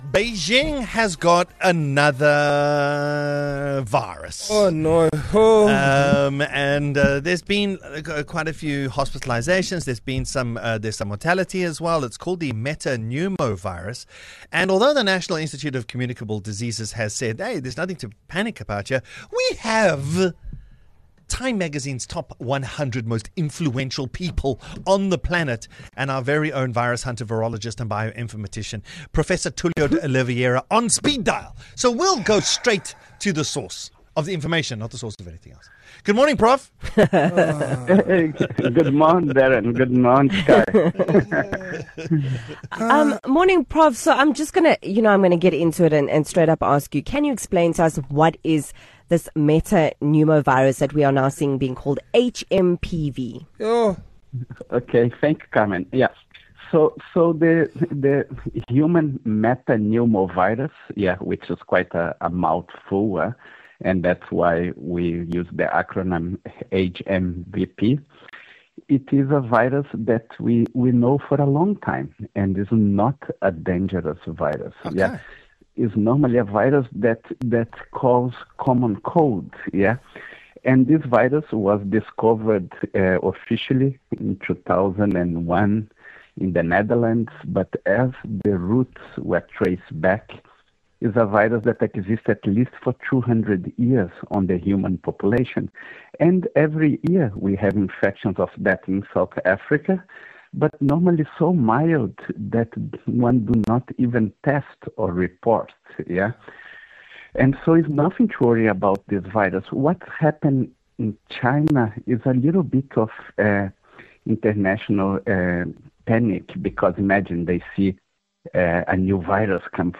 conversation with one of TIME100’s most influential people of 2024